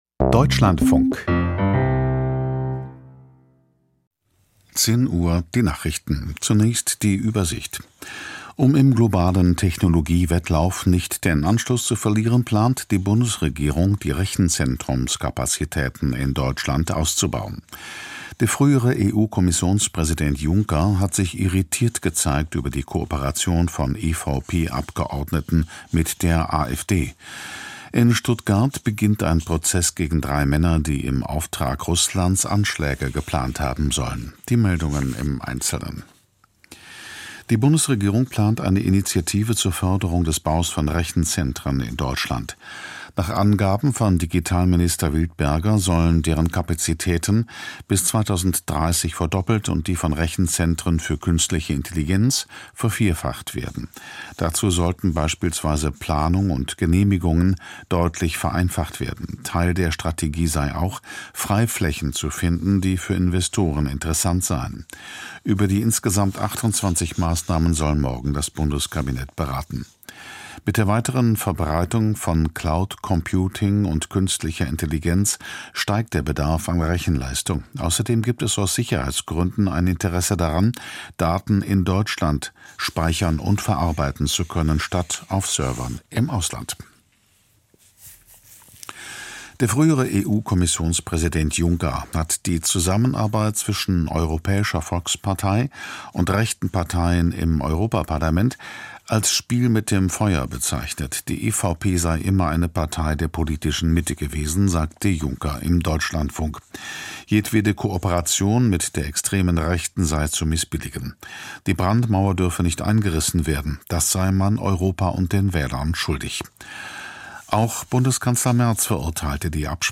Die Nachrichten vom 17.03.2026, 10:00 Uhr
Aus der Deutschlandfunk-Nachrichtenredaktion.